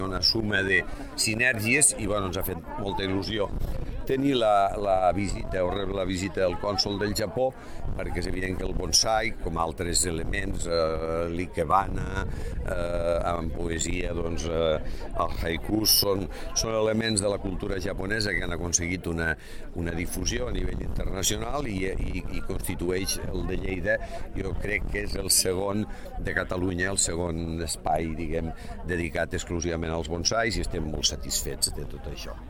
Corte de voz
miquel-pueyo-museu-bonsai.mp3